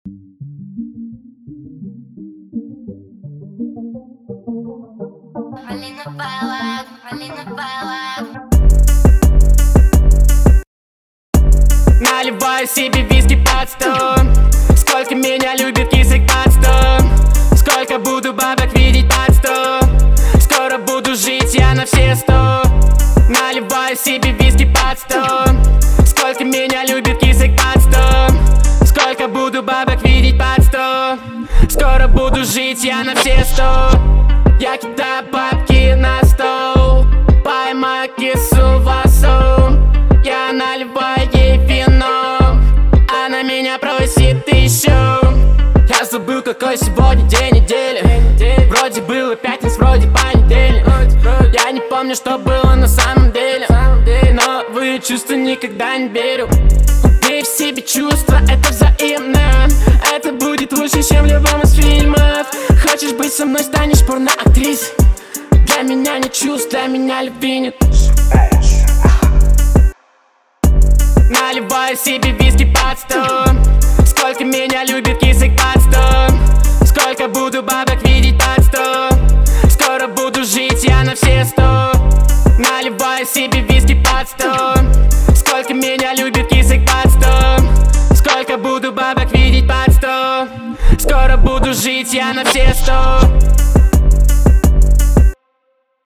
Жанр: rusrap